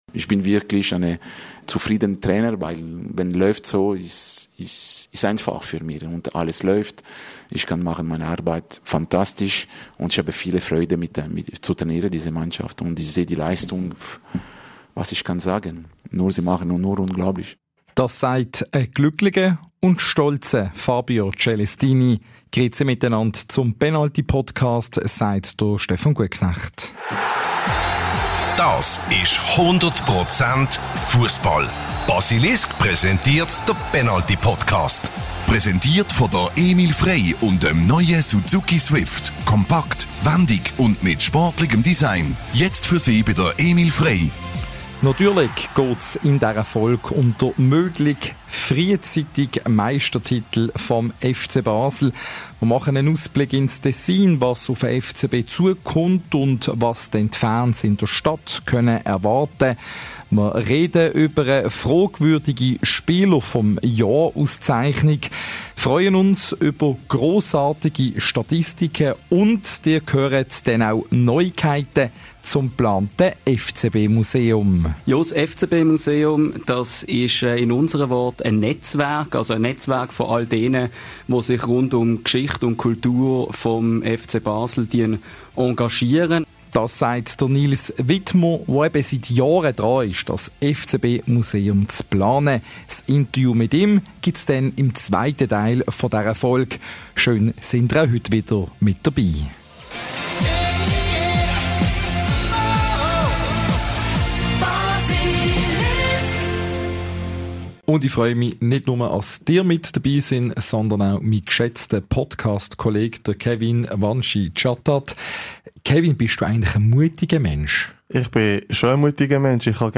Dabei werden Experten, Spieler und Fans interviewt, es wird gefachsimpelt und gelacht.